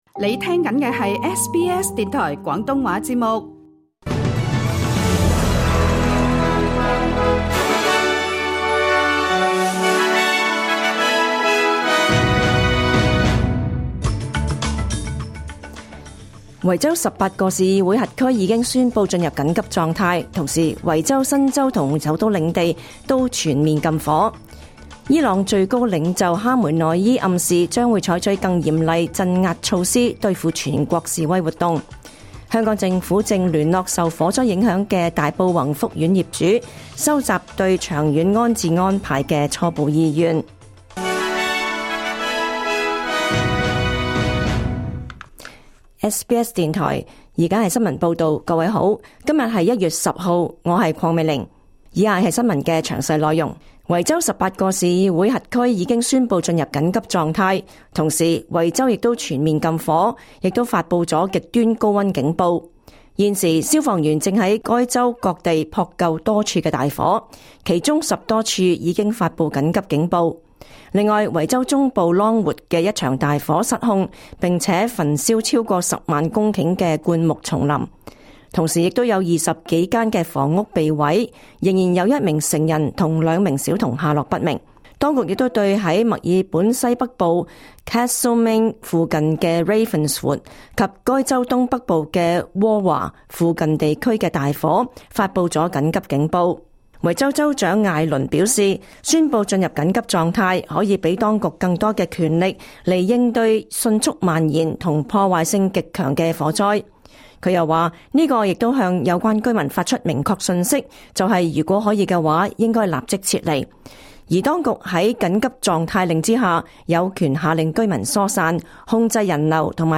2026 年 1 月 10 日 SBS 廣東話節目詳盡早晨新聞報道。